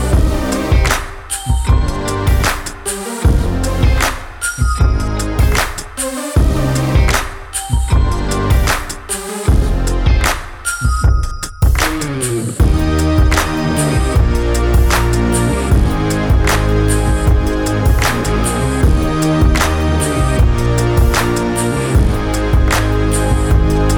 no Backing Vocals R'n'B / Hip Hop 5:08 Buy £1.50